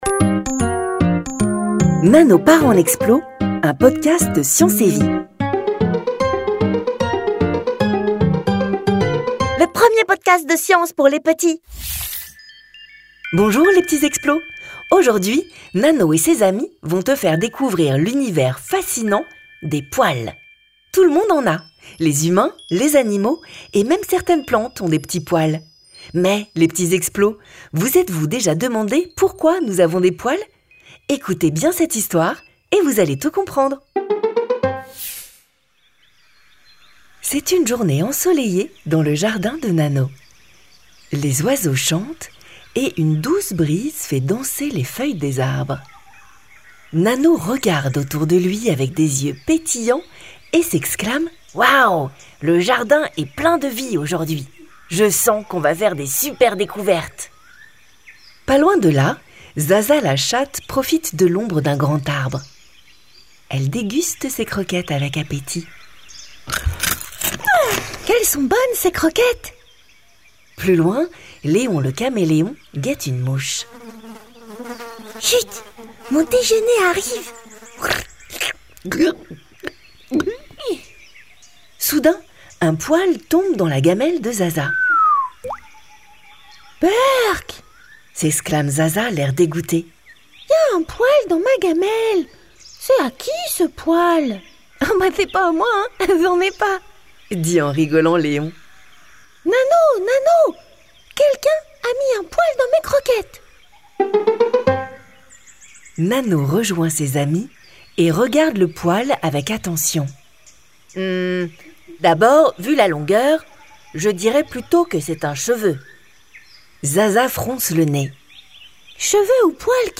Le podcast utilise des histoires simples, des sons immersifs et des activités interactives pour captiver et instruire les jeunes auditeurs.